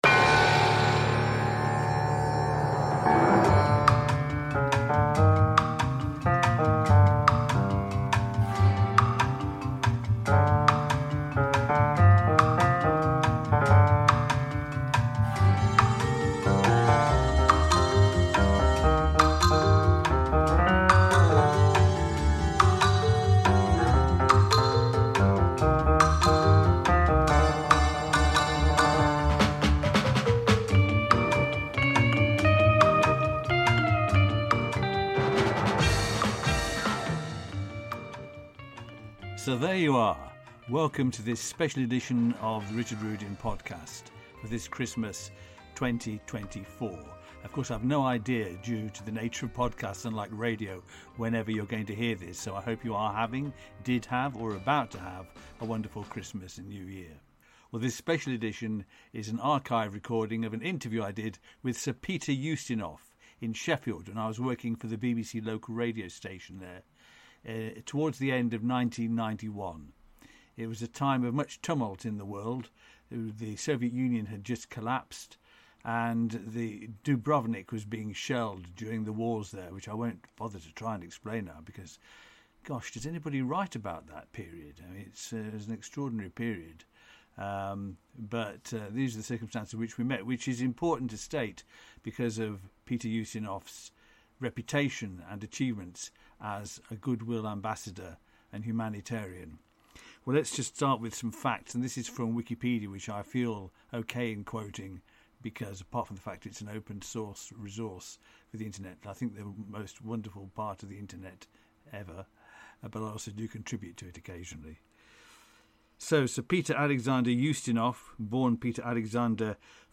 Peter Ustinov interview
A Christmas special podcast! An archive interview from 1991 with me and one of the best-connected and fascinating people in the world.